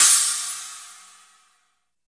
39 CRASH CYM.wav